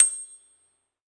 53s-pno29-C7.aif